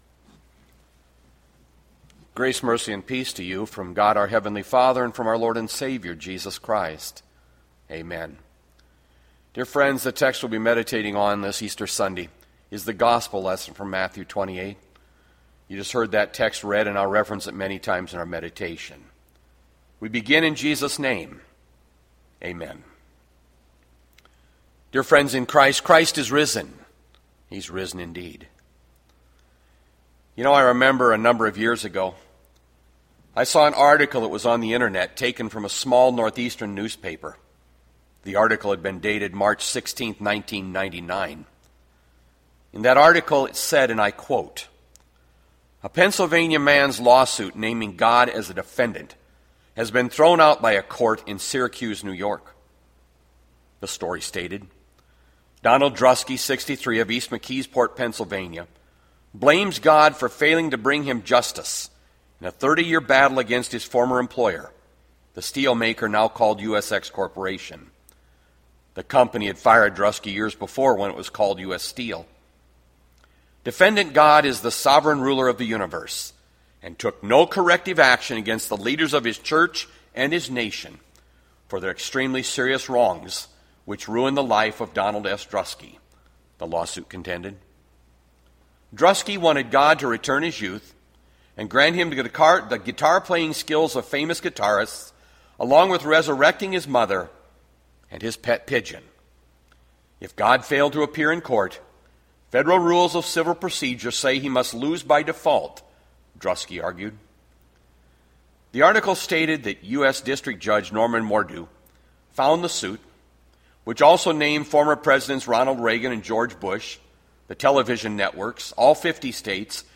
Bethlehem Lutheran Church, Mason City, Iowa - Sermon Archive Apr 12, 2020